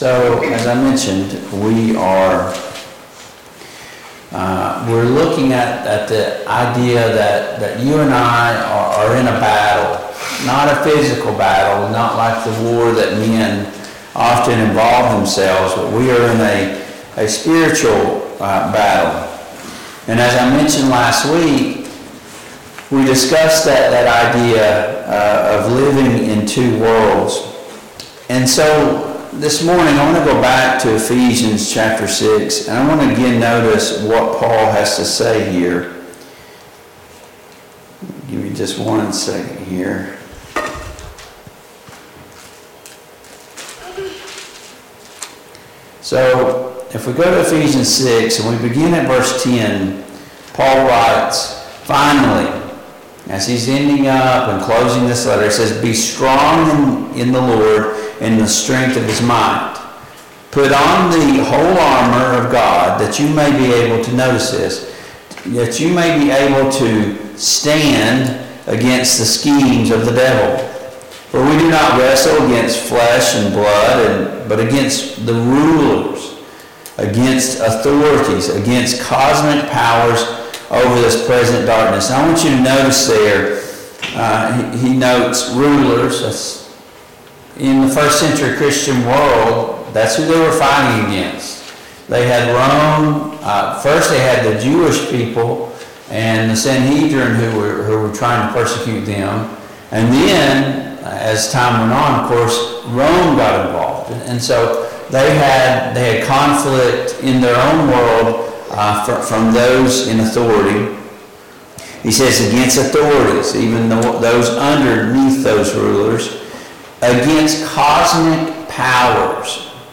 Service Type: Sunday Morning Bible Class